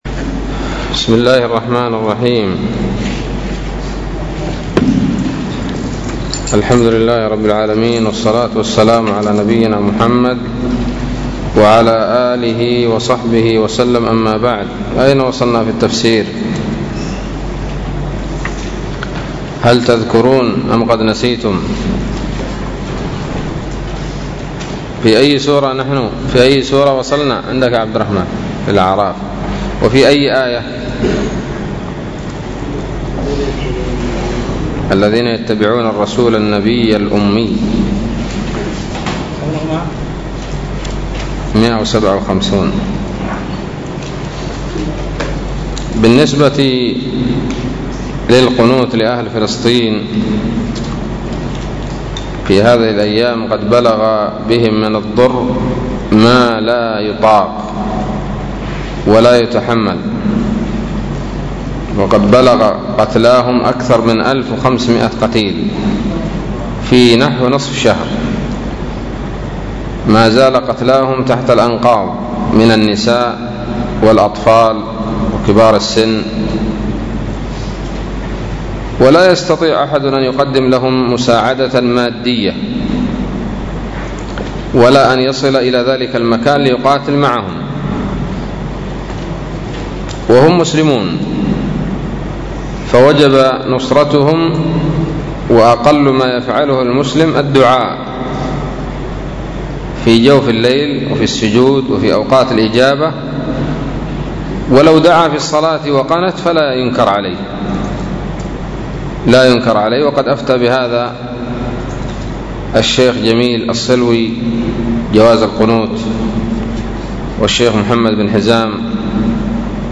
الدرس التاسع والأربعون من سورة الأعراف من تفسير ابن كثير رحمه الله تعالى